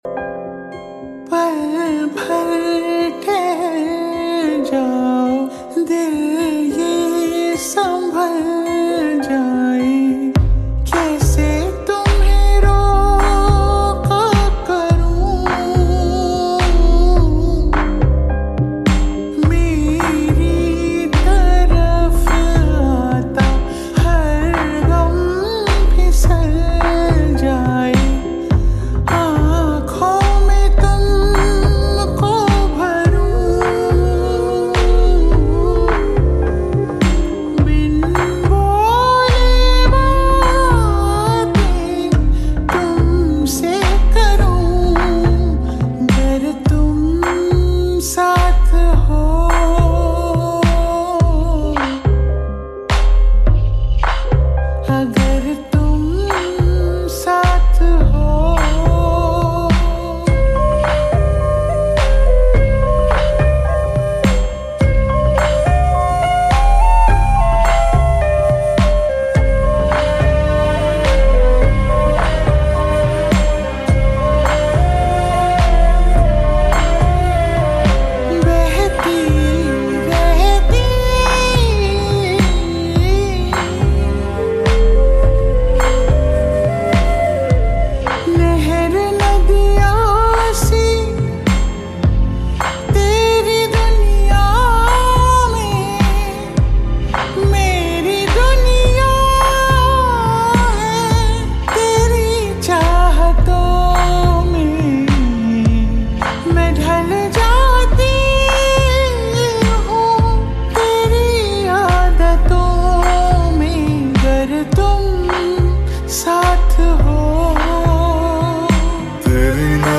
sloe x reverb